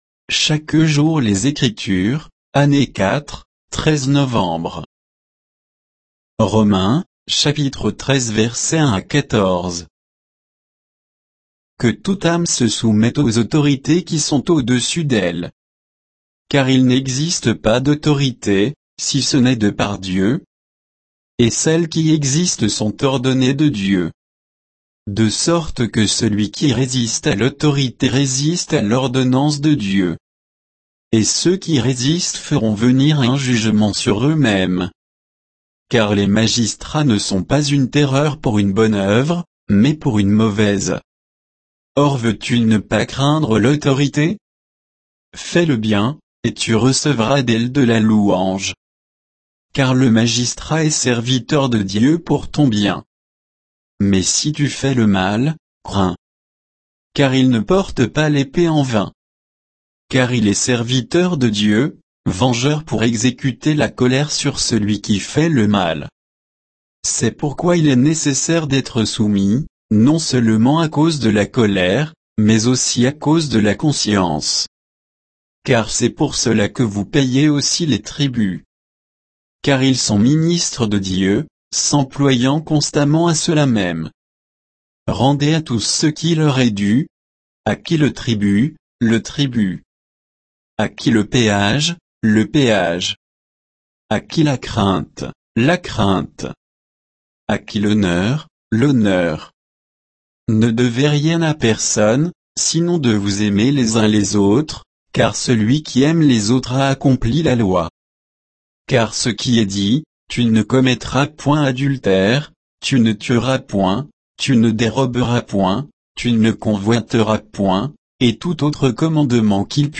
Méditation quoditienne de Chaque jour les Écritures sur Romains 13, 1 à 14